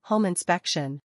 hoʊm - uhn · spek · shn